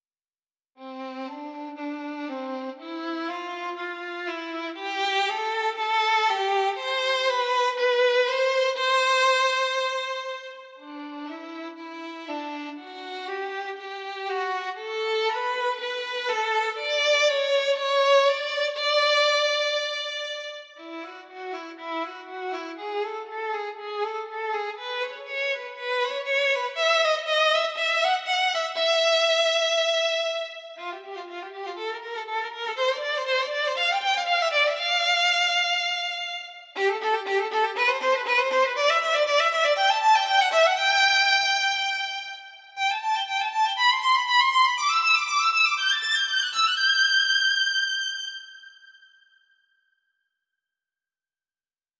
Suppose I have a melody (for example, Solo Violin, since I have that one) which goes c-d-d-e; and suppose I want:
It shows some possibilities how I would play your notes:
1. Legato-legato / gap (simulates the changing bow direction) Legato-Legato.
2. Sustain-Legato / gap / Sustain-Legato
3. Détaché long-Legato /gap / Détaché long-Legato (faster play)
5. sfz-fast Legato /gap / sfz-fast Legato (faster play more agressive)
But when you listen to result it sounds quit good (real) as well.
Single-Comb-Legato.wav